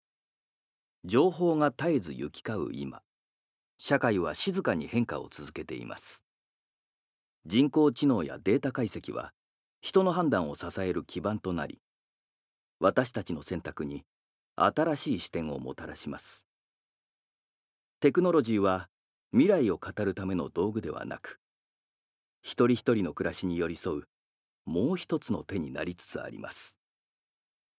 Voice Sample
ナレーション１